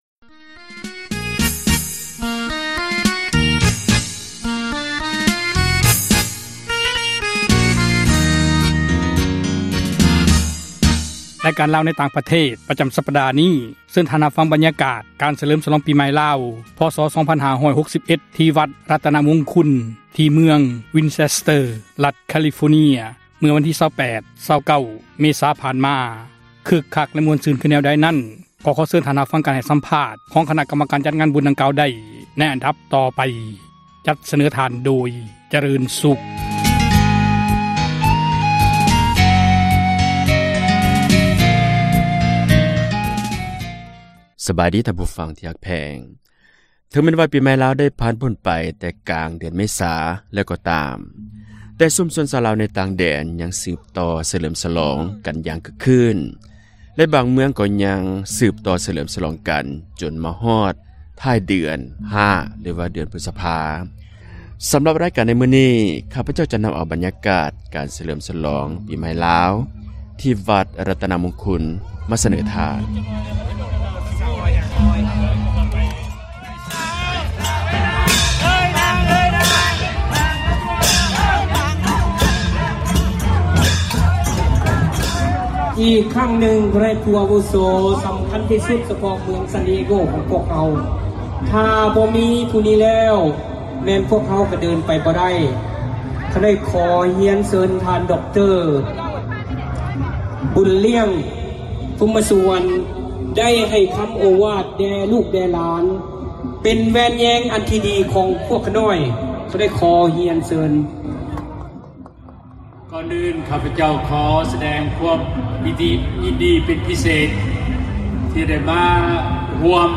ຣາຍການ ຊີວິດຊາວລາວໃນຕ່າງປະເທດ ສຳລັບທ້າຍສັປດານີ້, ເຊີນທ່ານ ຮັບຟັງ ບັນຍາກາດການສເລີມສລອງປີໃໝ່ ພ.ສ. ໒໕໖໑ ທີ່ວັດຣັຕນະມຸງຄຸນ, ເມືອງ ວິນເຈັສເຕີ, ຣັຖ ແຄລີຟໍເນັຽ, ເມື່ອວັນທີ ໒໘-໒໙ ເມສາຜ່ານມາ, ພ້ອມກັບສັມພາດ ຄນະກັມມະການ ຈັດງານ ດັ່ງກ່າວ.